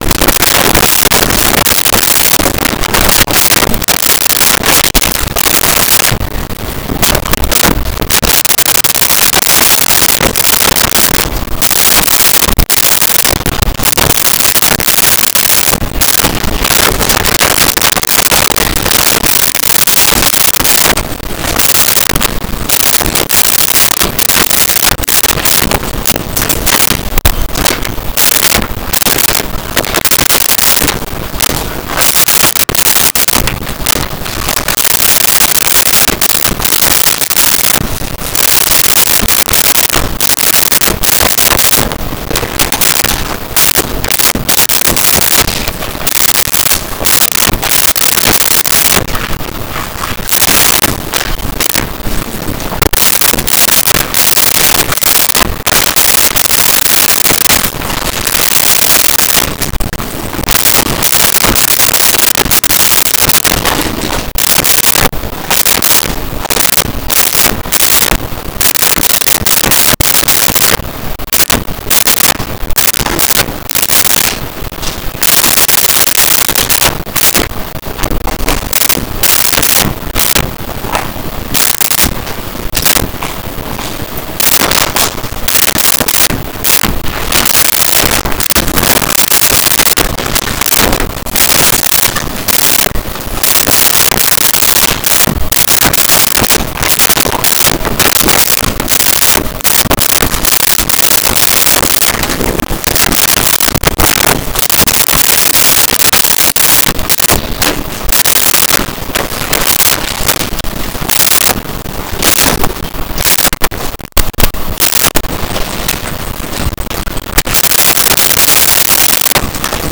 Office Interior
Office Interior.wav